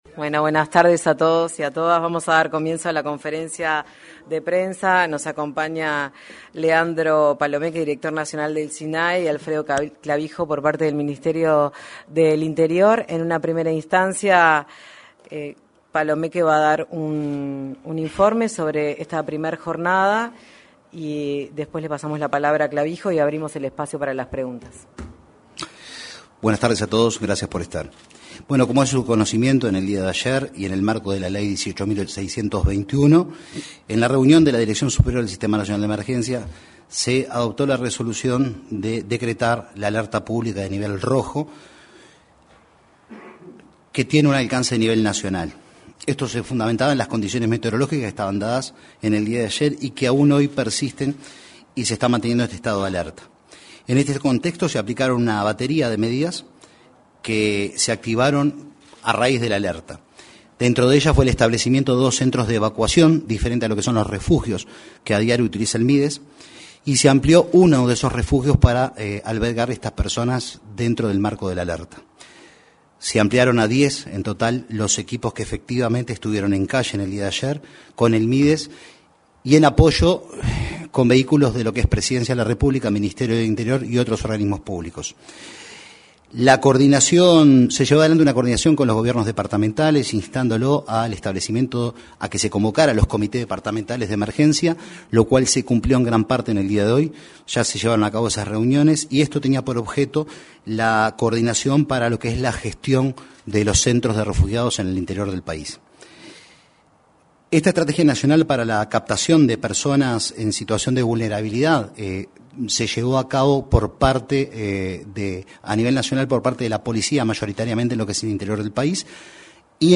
Conferencia de prensa del Sistema Nacional de Emergencias
Conferencia de prensa del Sistema Nacional de Emergencias 24/06/2025 Compartir Facebook X Copiar enlace WhatsApp LinkedIn El director del Sistema Nacional de Emergencias (Sinae), Leandro Palomeque, y el subdirector de la Policía Nacional, Alfredo Clavijo, informaron, en una conferencia de prensa en la Torre Ejecutiva, sobre las acciones para proteger a personas en situación de calle ante las bajas temperaturas.